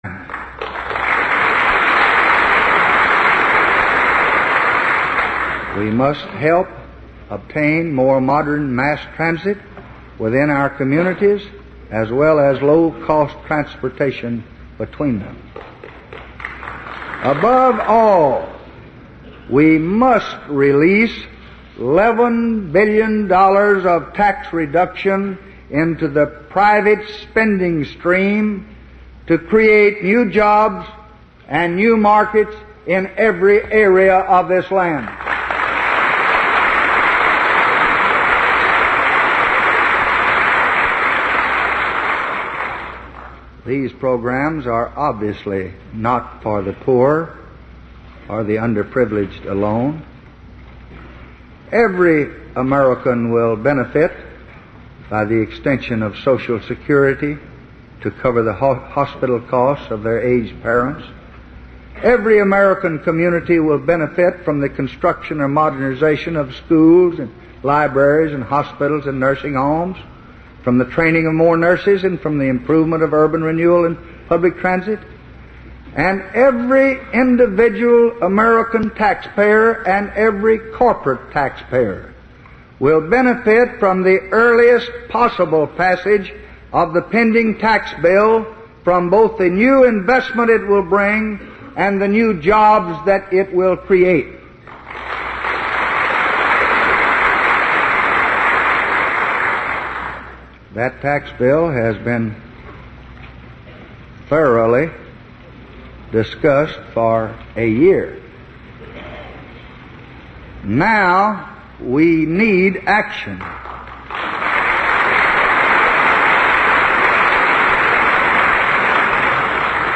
Tags: Lyndon Baines Johnson Lyndon Baines Johnson speech State of the Union State of the Union address President